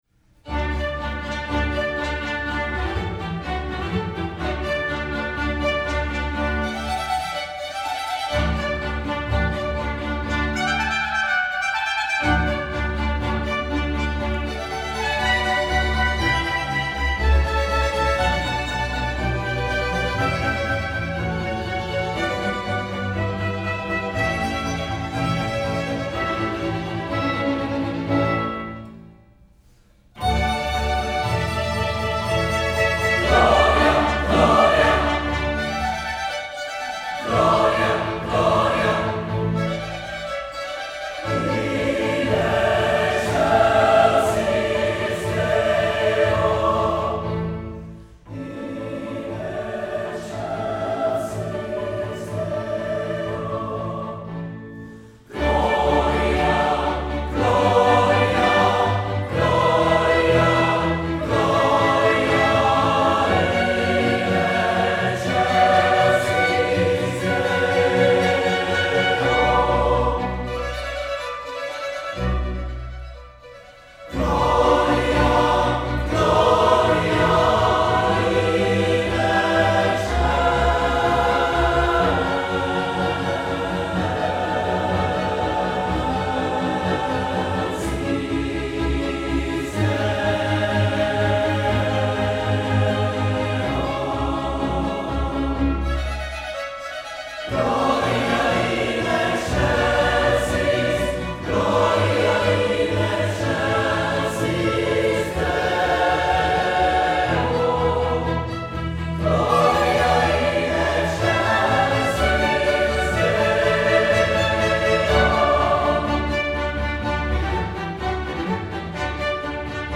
Chorus is a mixed-voice choir comprising about 75 students, faculty, staff, and community members.
Swarthmore College Chorus sings Vivaldi
Recorded at the Spring 2015 Concert